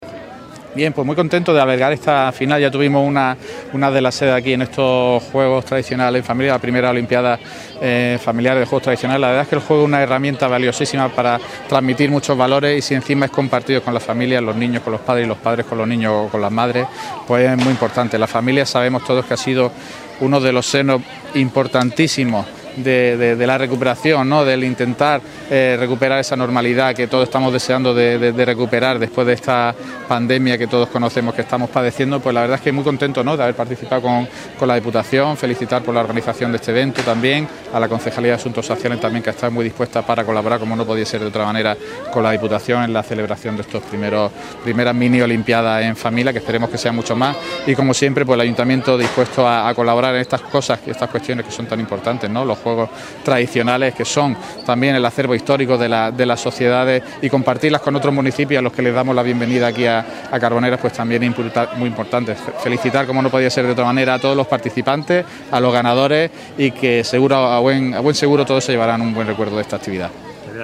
Corte-Alcalde-de-Carboneras-Dia-de-la-Familia.mp3